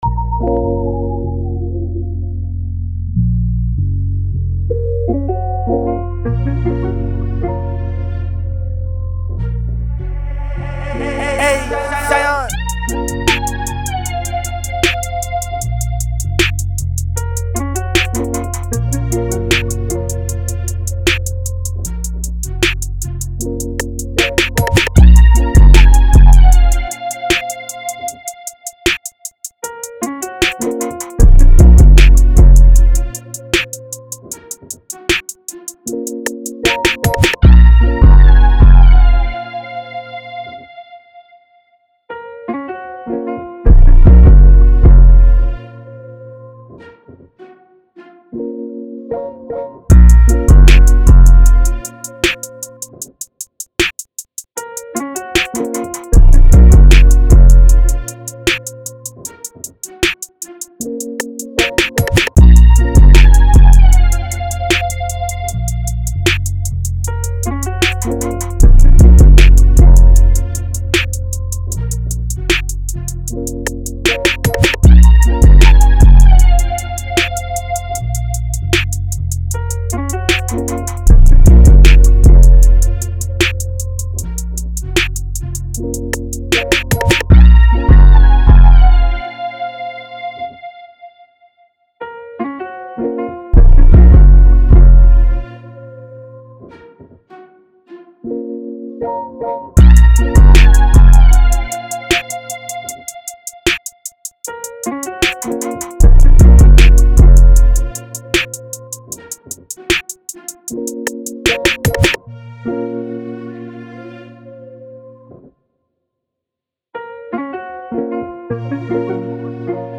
154 B Minor